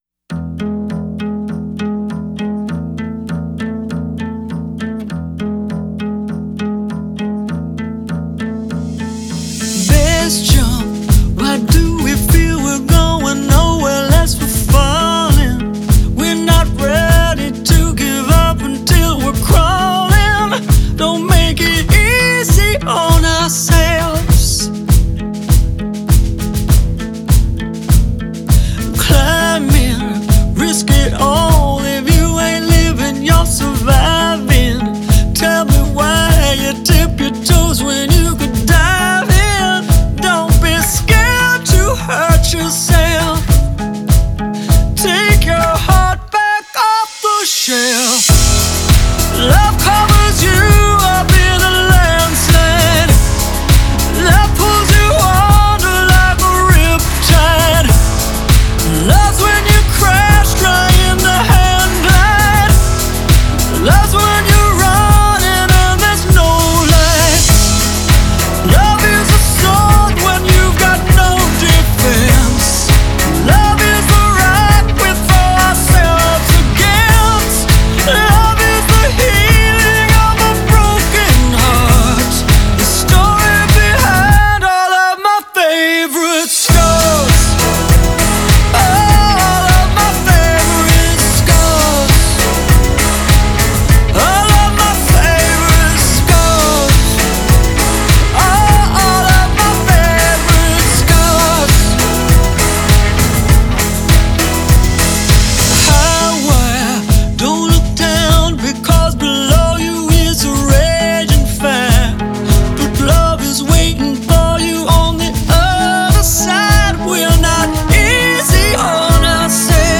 장르: Electronic, Pop
스타일: Dance-pop, House, Electro House, Ballad